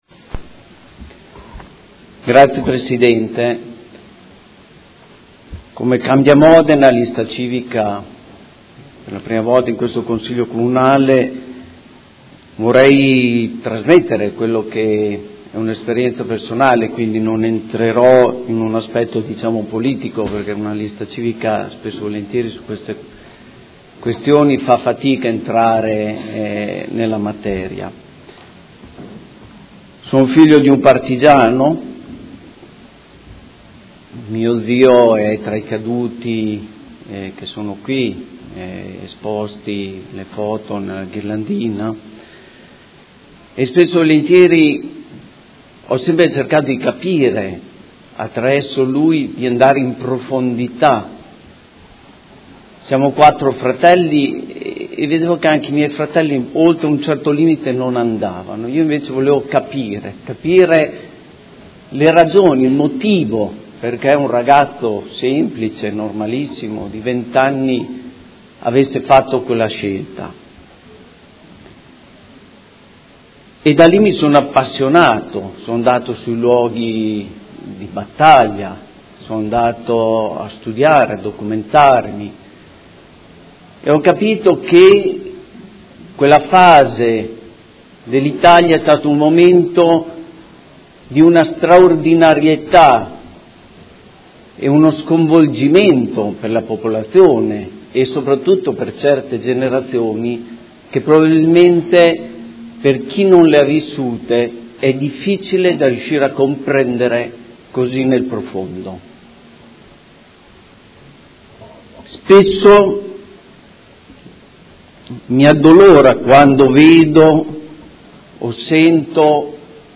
Seduta del 20/04/2016. Dibattito su Celebrazione del 70° dall'insediamento del primo Consiglio Comunale di Modena dopo il periodo fascista